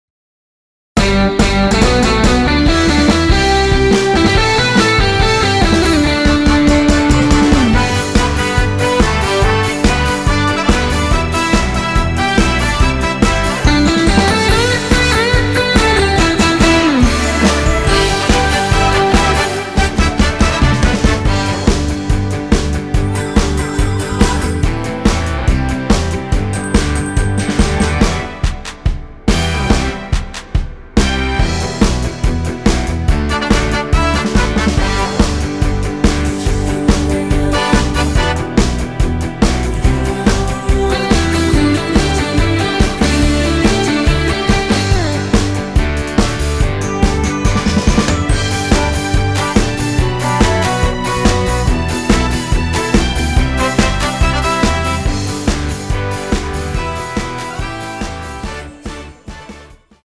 하이퀄리티 MR로 제작한 (-1)내린 코러스 MR 입니다.
◈ 곡명 옆 (-1)은 반음 내림, (+1)은 반음 올림 입니다.
앞부분30초, 뒷부분30초씩 편집해서 올려 드리고 있습니다.
중간에 음이 끈어지고 다시 나오는 이유는